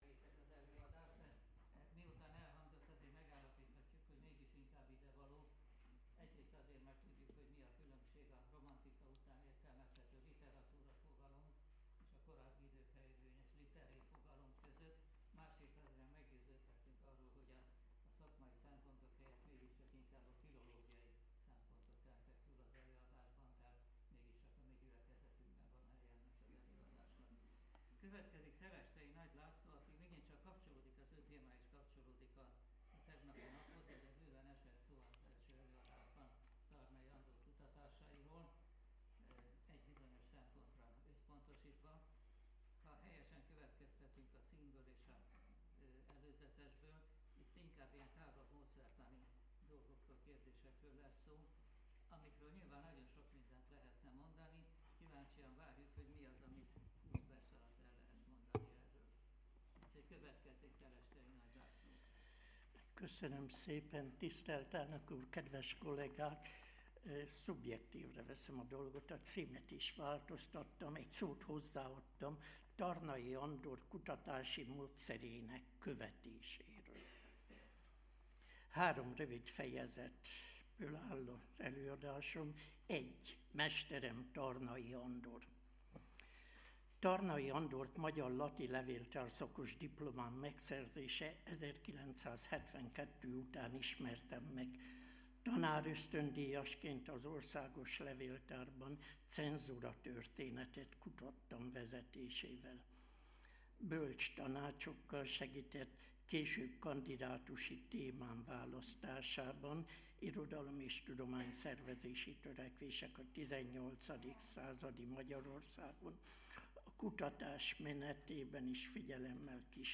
Hagyományőrzés és önbecsülés. Száz éve született Tarnai Andor , Harmadik ülés